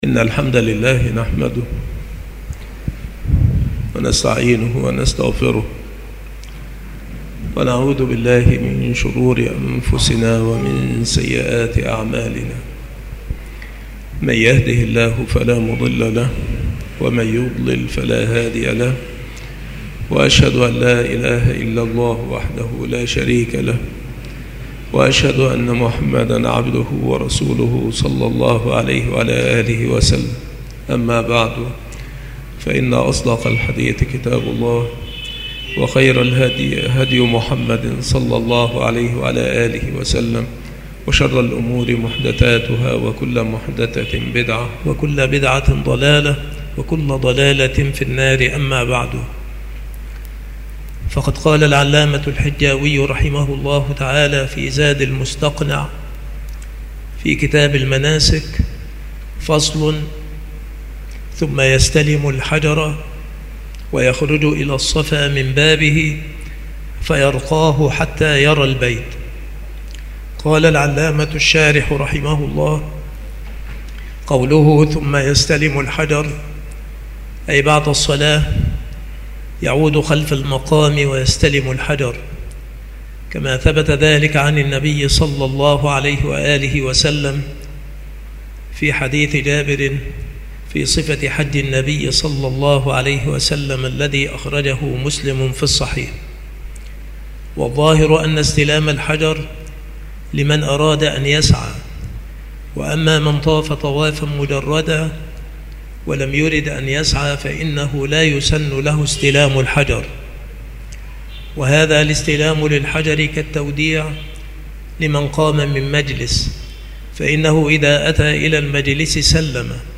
مكان إلقاء هذه المحاضرة بالمسجد الشرقي بسبك الأحد - أشمون - محافظة المنوفية - مصر عناصر المحاضرة : استلام الحجر الأسود بعد صلاة الركعتين. ما يقول إذا دنا من الصفا. ما يقوله على الصفا والمروة. سبب السعي، وحد المسعى. الطهارة والستارة والموالاة في السعي. ما يشرع بعد السعي. قطع التلبية للمتمتع والمعتمر. قطع التلبية للمفرد والقارن.